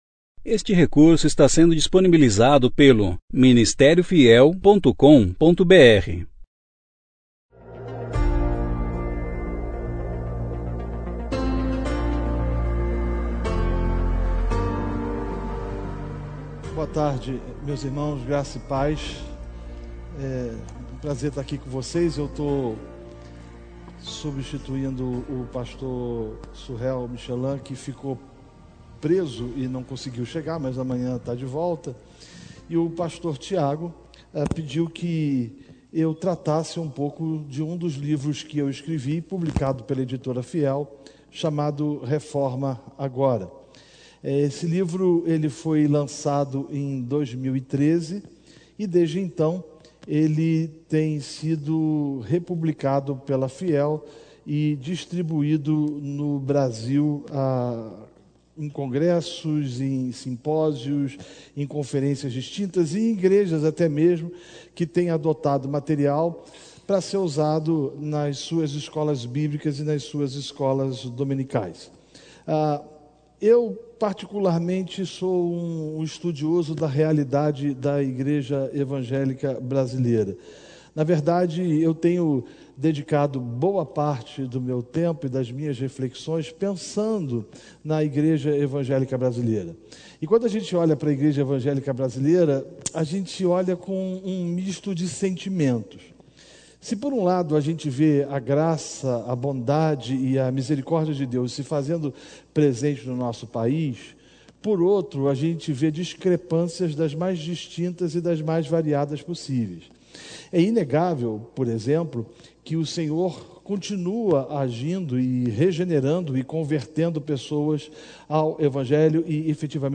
Palestra sobre livro Reforma Agora
Conferência: 35ª Conferência Fiel para Pastores e Líderes Tema